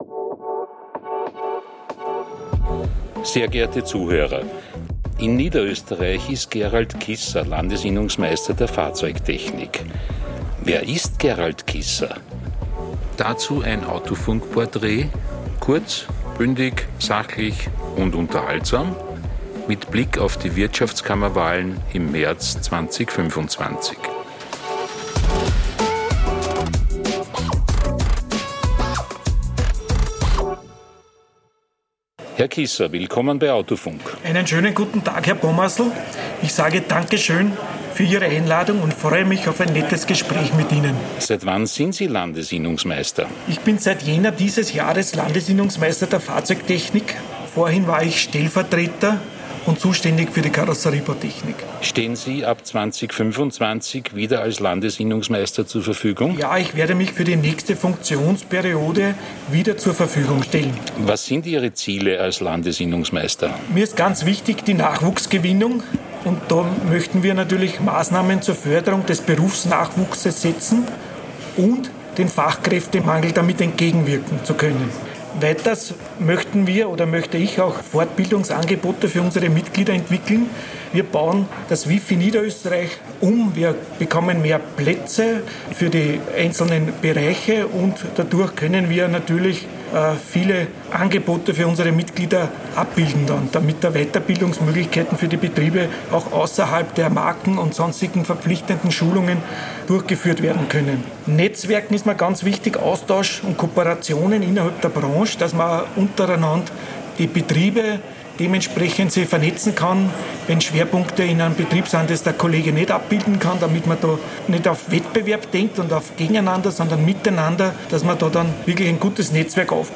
im Gespräch.